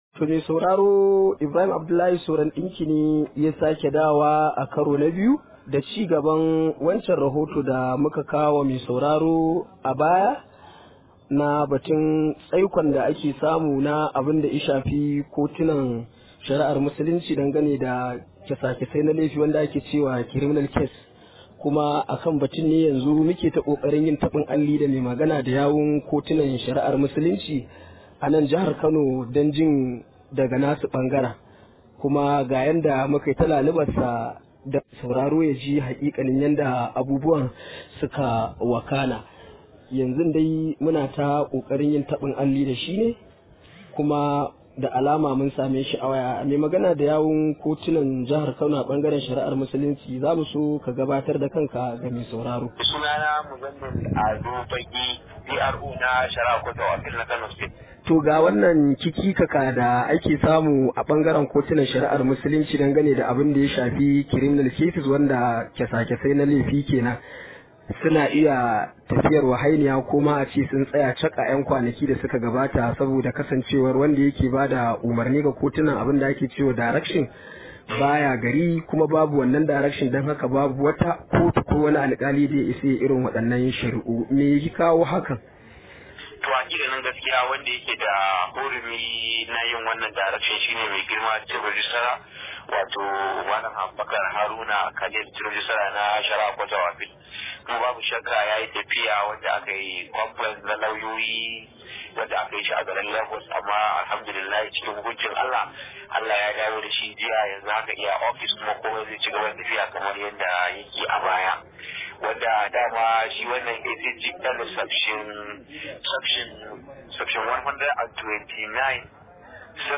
Rahoto: Kotunan musulunci sun dawo da sauraron shari’un laifi a Kano
Akwai cikakken rahoton a muryar da ke kasa.